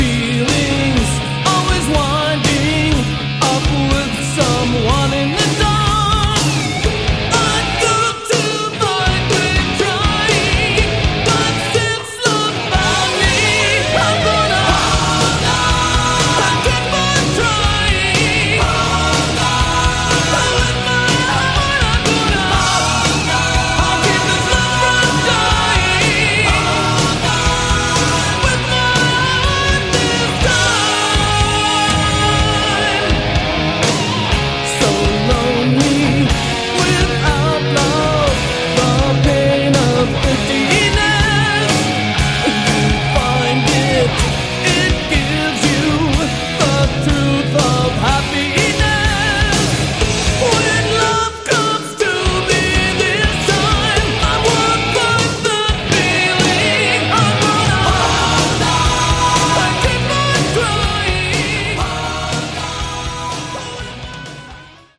Category: Hard Rock
vocals, acoustic guitar
keyboards, guitar
drums, backing vocals
single version